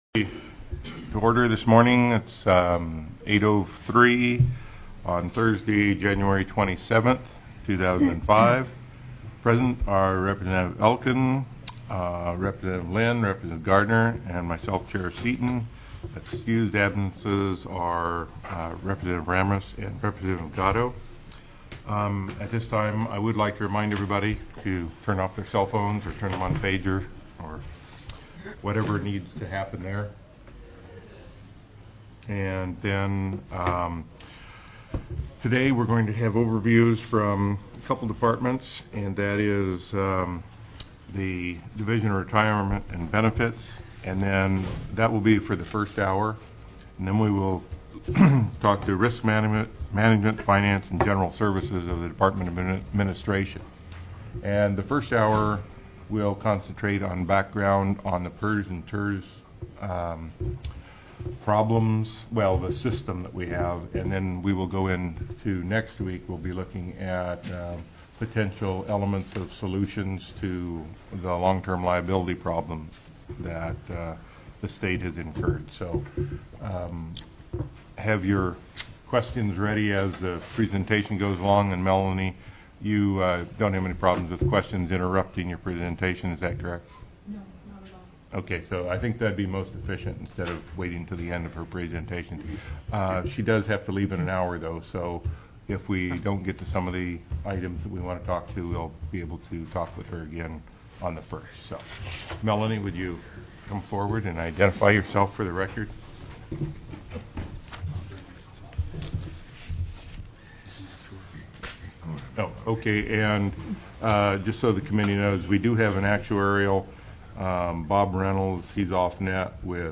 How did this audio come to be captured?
01/27/2005 08:00 AM House STATE AFFAIRS